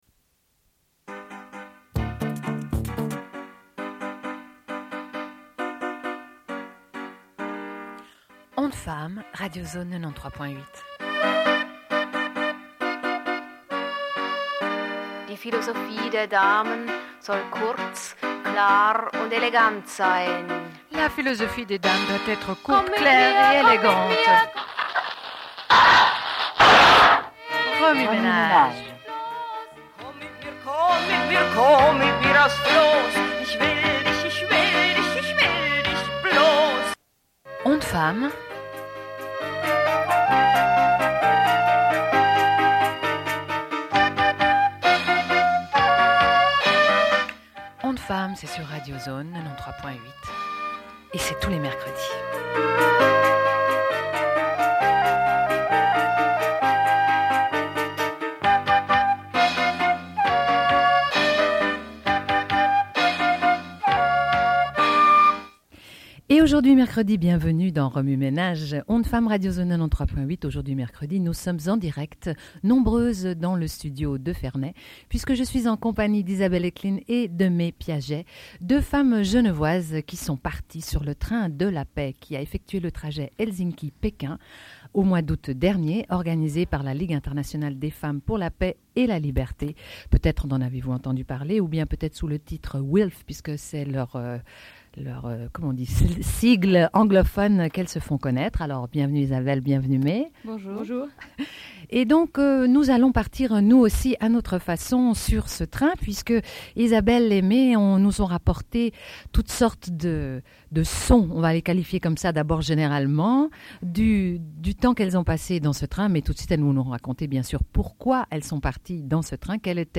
Elles diffusent des entretiens réalisés dans le train. À l'occasion de la Conférence internationale des femmes organisée par l'ONU ainsi que le Forum des femmes qui a lieu en parallèle.